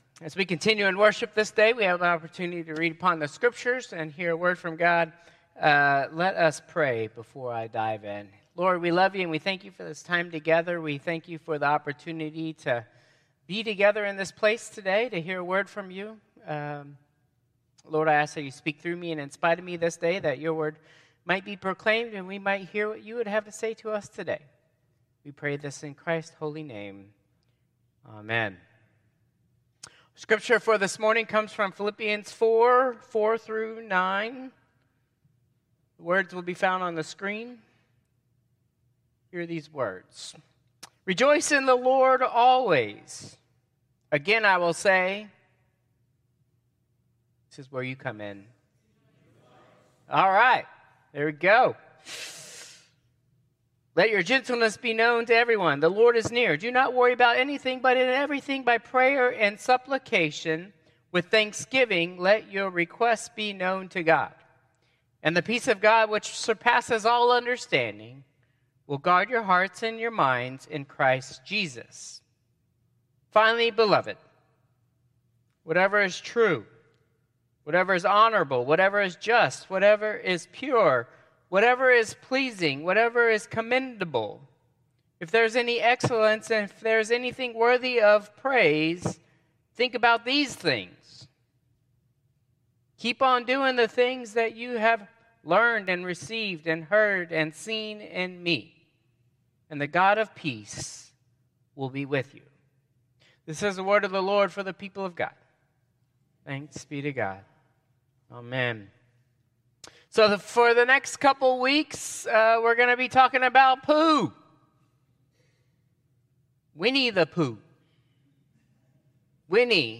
Traditional Service 11/17/2024